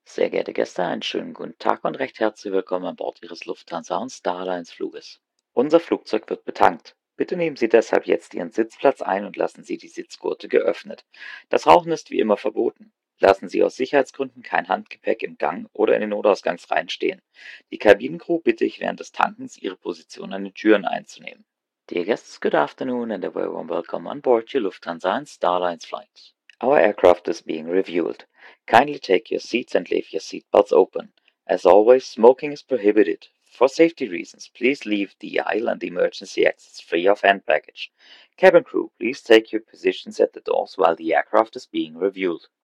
BoardingWelcome[Refueling][Afternoon].ogg